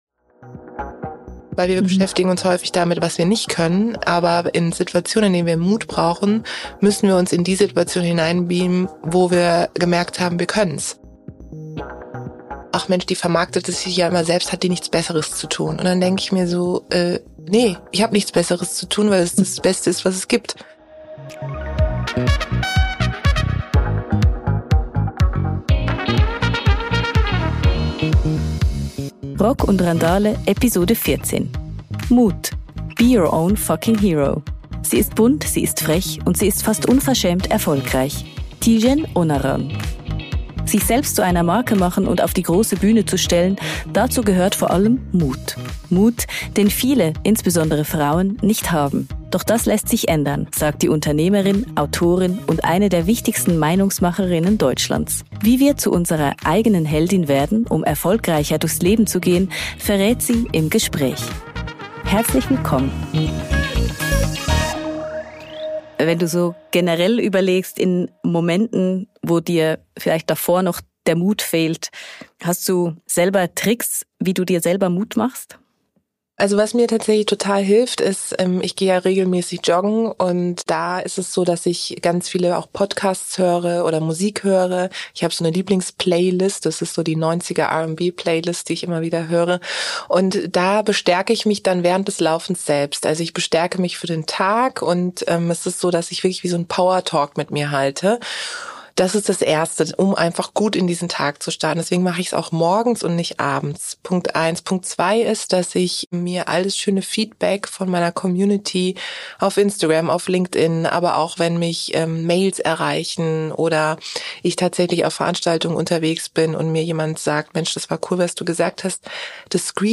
Wie werden wir also zu den Heldinnen und Helden unseres eigenen Lebens? Tijen Onaran verrät es im Gespräch – und stellt uns gleich die erste Mutprobe.